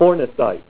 Say FORNACITE Help on Synonym: Synonym: ICSD 26828   PDF 15-200